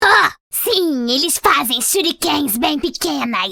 Kennen's iconic voice lines from League of Legends in Brazilian Portuguese.